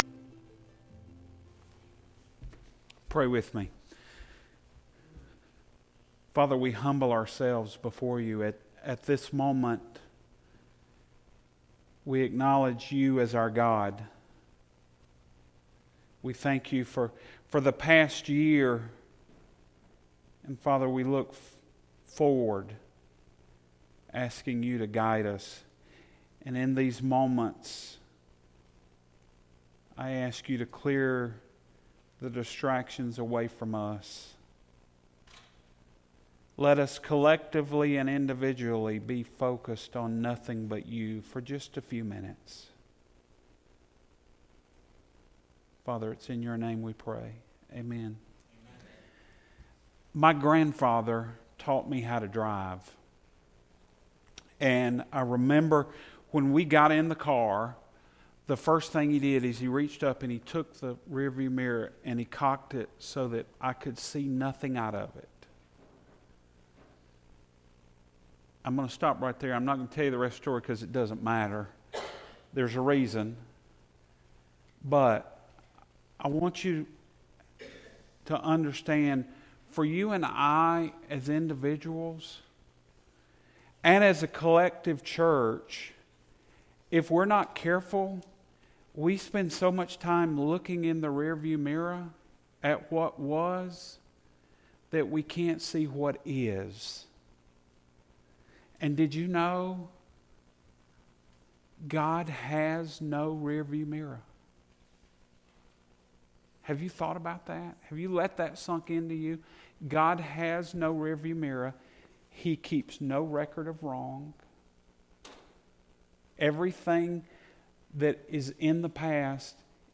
Sunday Morning Sermon
Audio Full Service